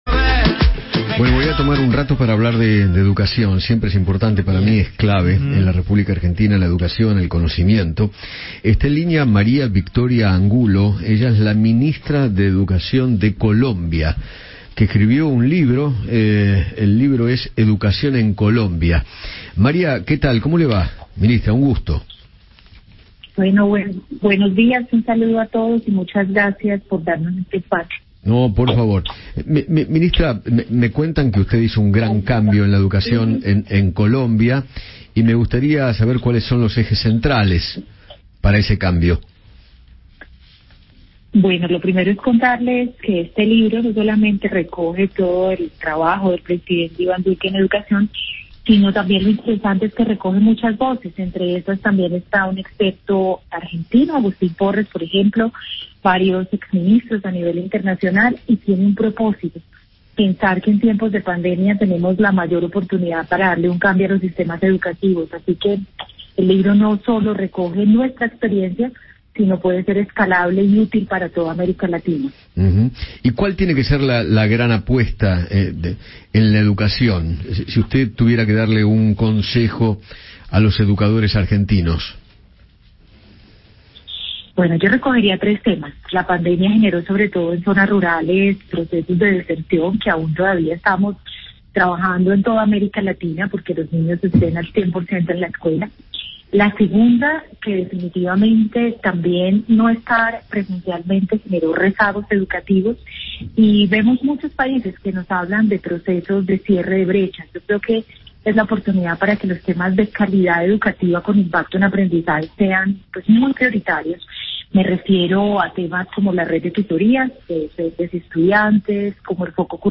María Victoria Angulo, ministra de Educación de Colombia, conversó con Eduardo Feinmann sobre las modificaciones que llevó a cabo en la educación colombiana y detalló cuales fueron los ejes centrales para ese cambio.